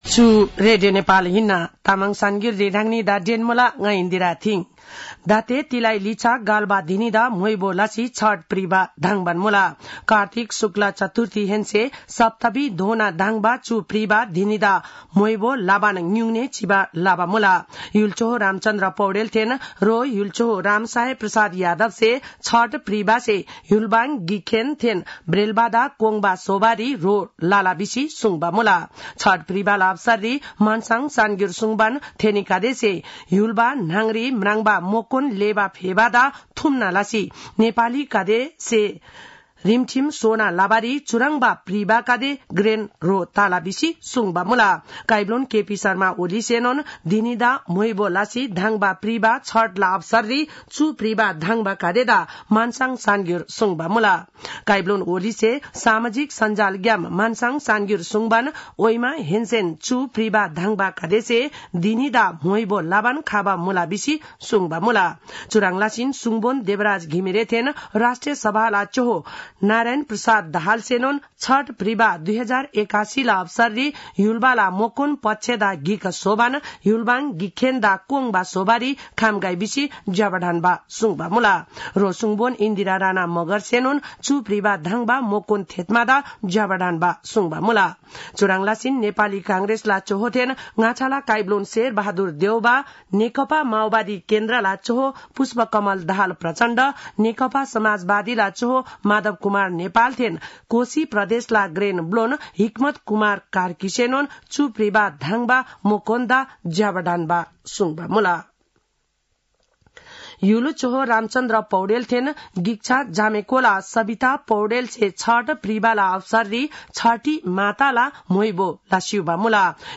तामाङ भाषाको समाचार : २३ कार्तिक , २०८१